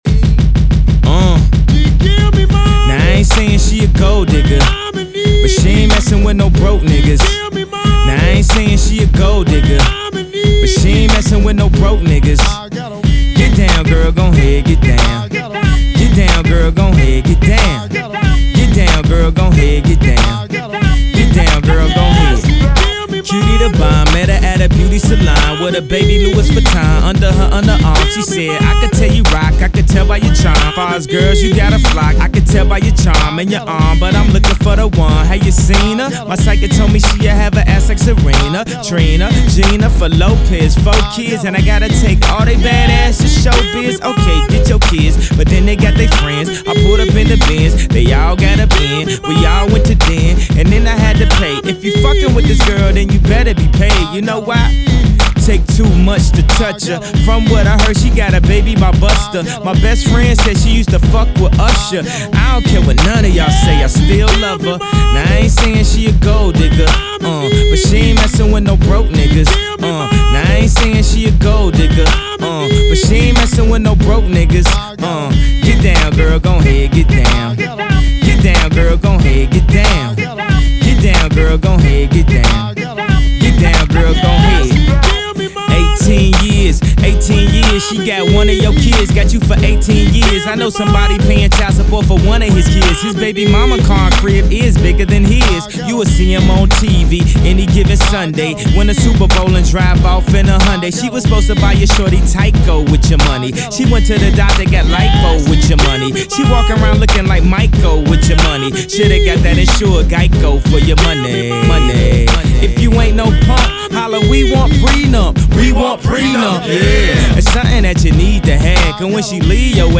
DJ | MC | Vocalist